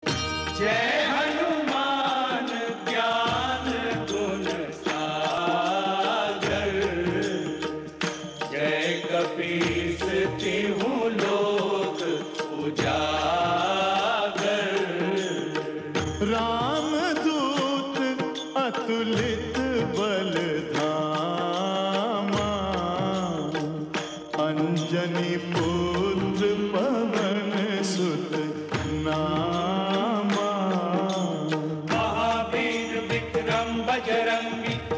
Instrumental Ringtone
Instrumental